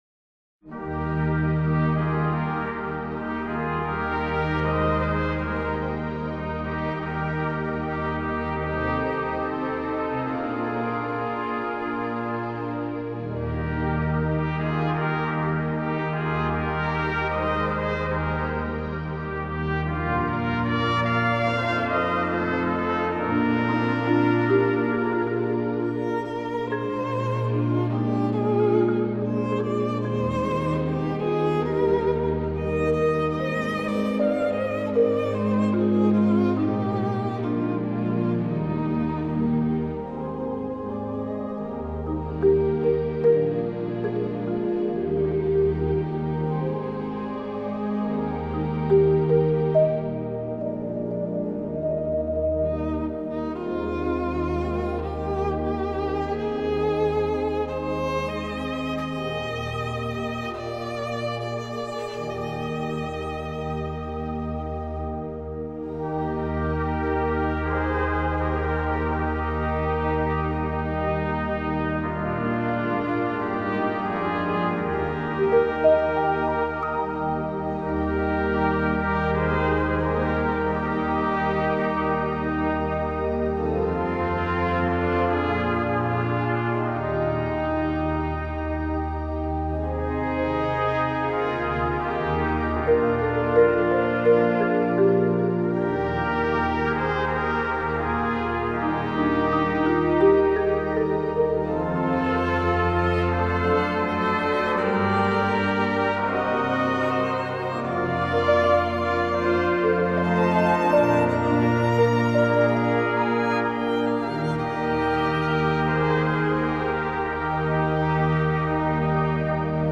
Als ge in nood gezeten – trompet mp3 :